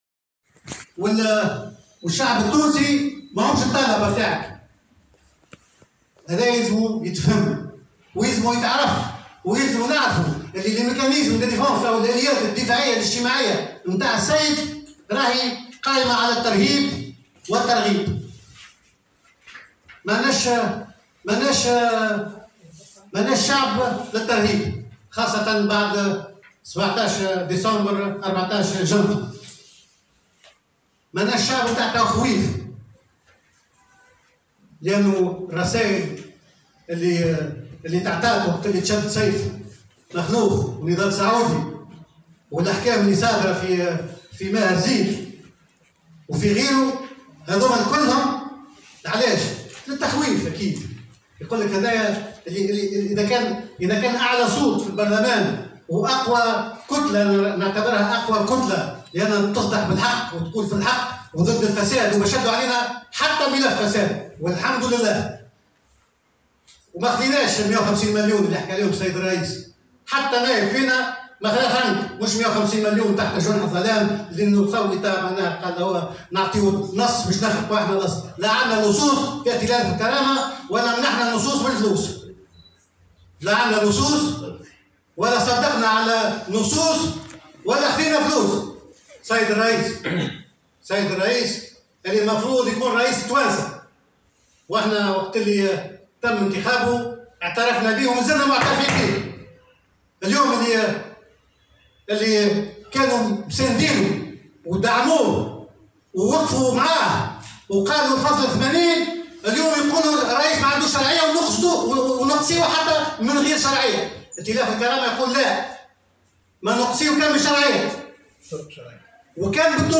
وقال الدالي، خلال ندوة صحفية نظمها ائتلاف الكرامة اليوم السبت، إن رئيس الجمهورية انتهج سياسة التخويف ضد كتلة ائتلاف الكرامة، التي وصفها بأنها كانت أقوى كتلة نيابية وصاحبة الصوت الأعلى في البرلمان، كونها كانت دائما تصدح بالحق، بحسب تعبيره.